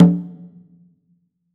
GONGA LOW.wav